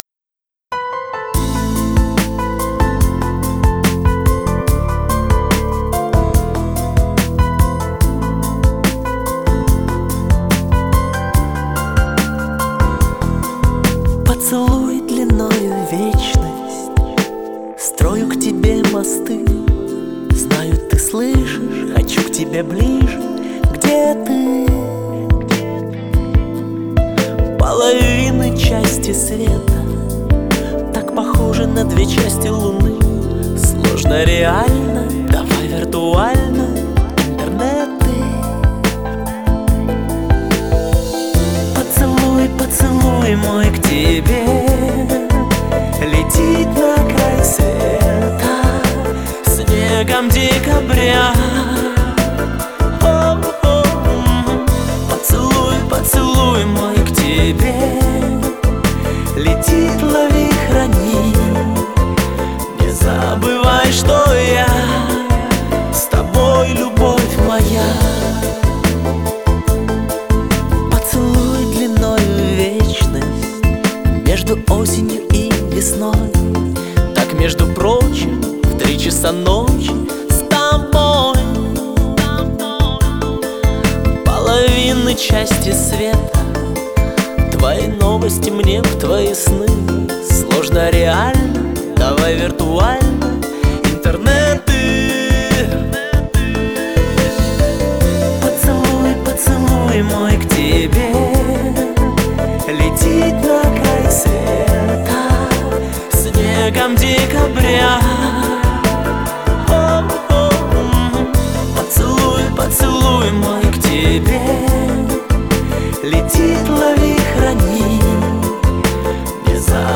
Жанр: Pop